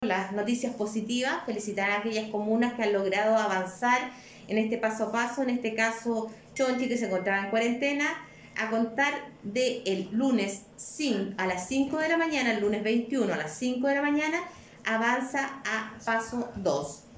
Así lo explicó la Seremi de Salud (s) de Los Lagos, Marcela Cárdenas.